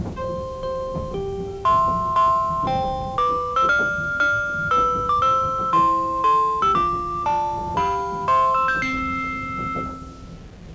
私が今までに収録してきた、ささやかな車内放送チャイム集です。
183系チャイム(その2)[183-chb.wav/231KB]
「雪山讃歌」です。冬場の長野県内の区間で流れるのでしょうね…